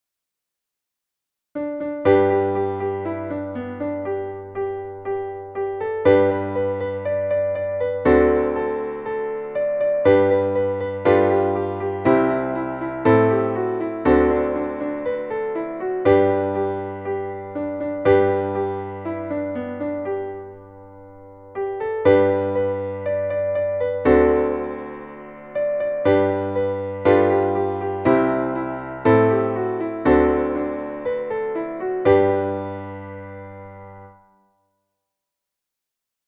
Traditionelles Kinderlied